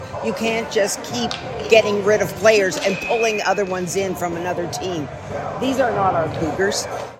One long-time supporter shared their thoughts.